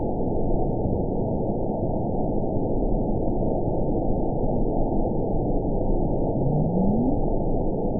event 917708 date 04/13/23 time 10:29:51 GMT (2 years, 1 month ago) score 9.13 location TSS-AB05 detected by nrw target species NRW annotations +NRW Spectrogram: Frequency (kHz) vs. Time (s) audio not available .wav